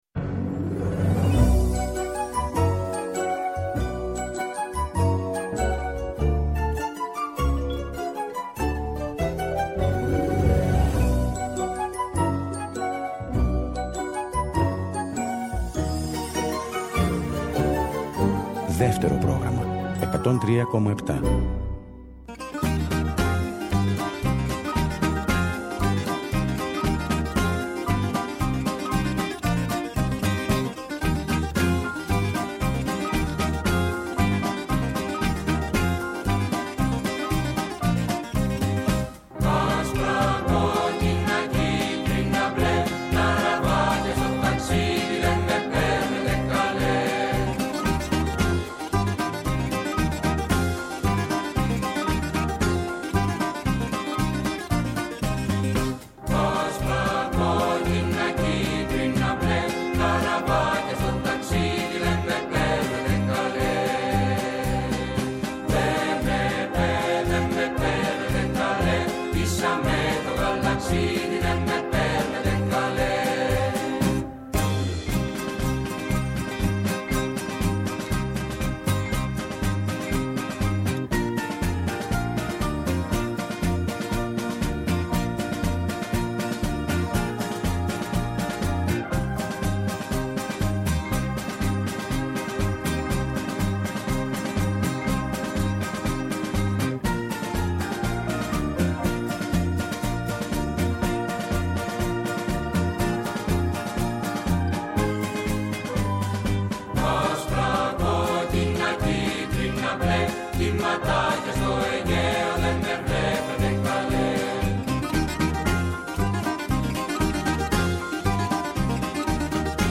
ο οποίος παίζει και τραγουδά με την κιθάρα του 3 τραγούδια